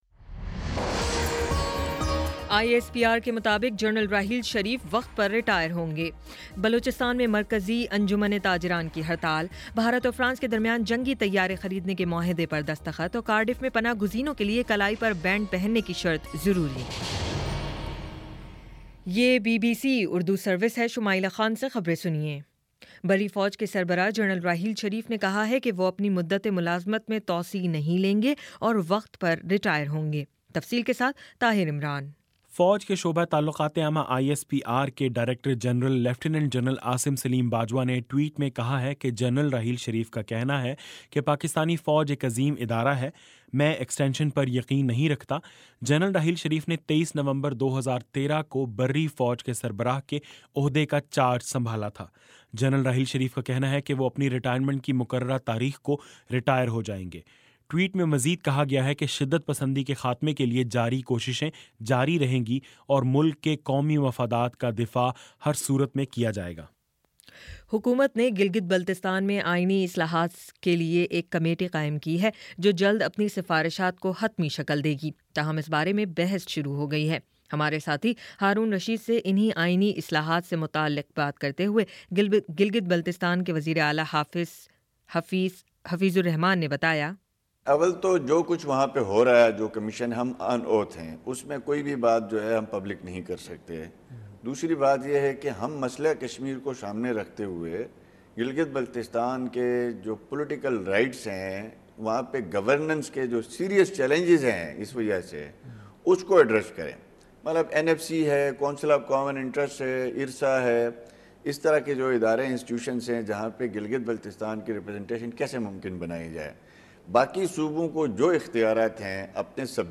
جنوری 25 : شام چھ بجے کا نیوز بُلیٹن